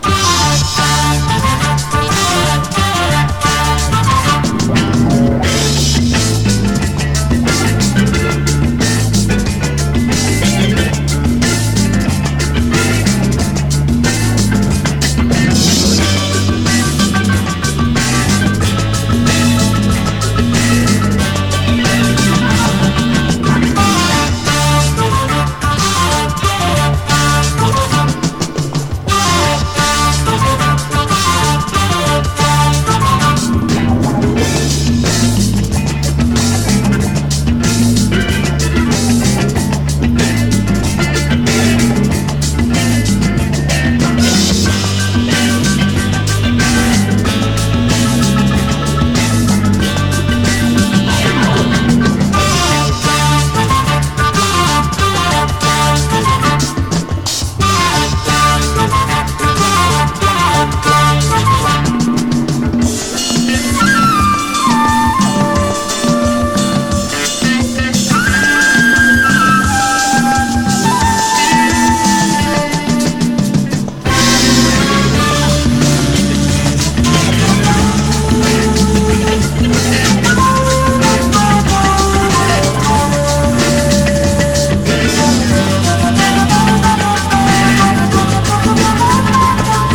フロア・ユースな踊れるジャズ名曲選！